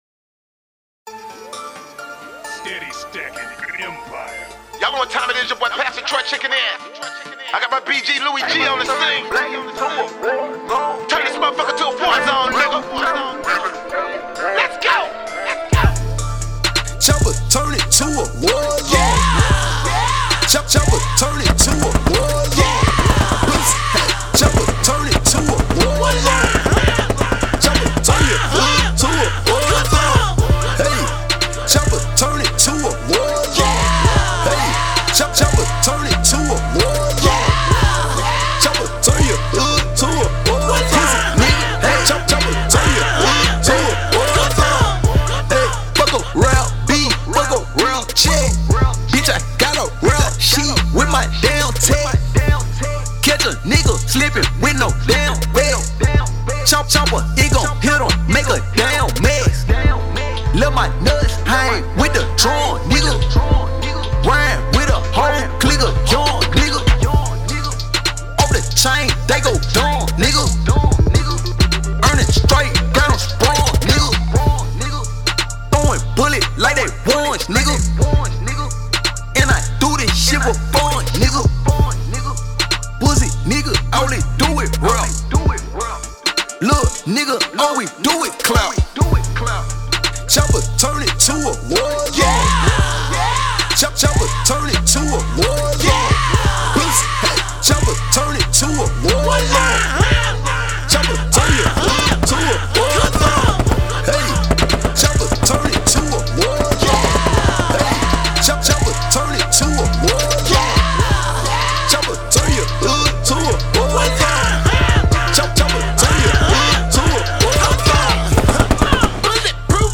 Hiphop
HIP HOP